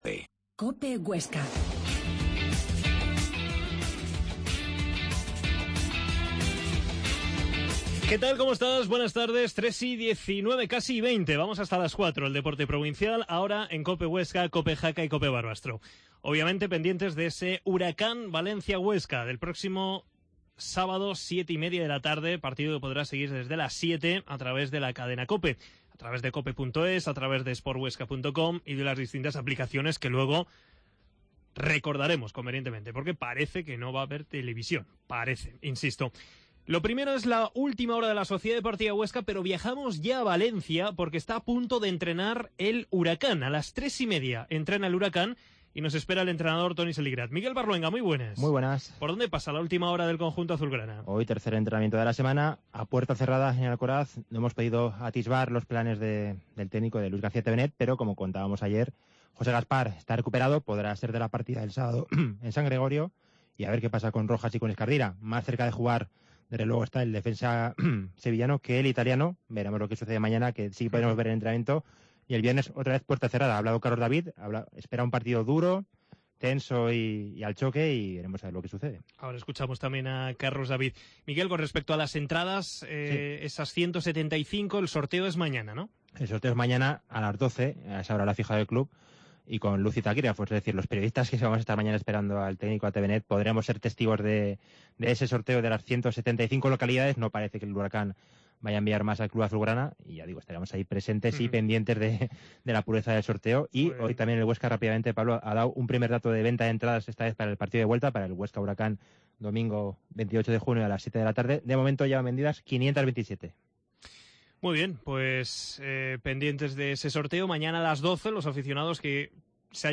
AUDIO: Actualidad del Huesca, entrevista